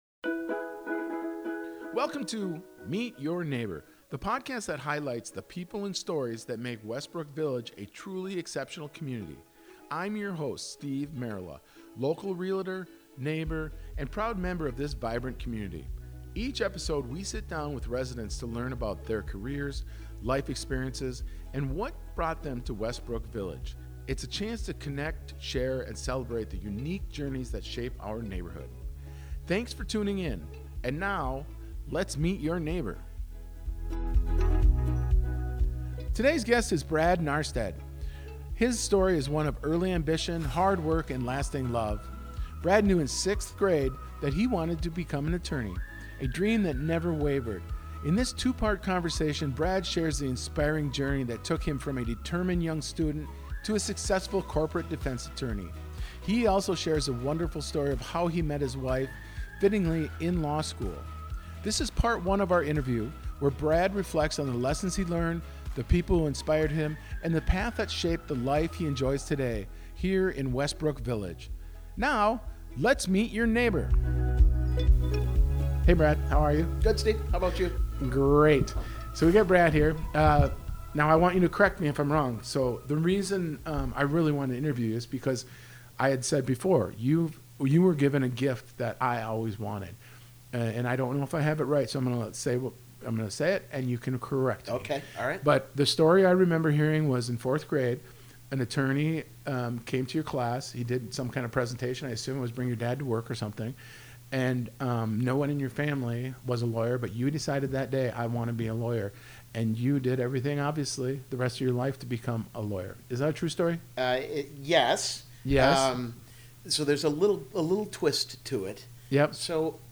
In this two-part conversation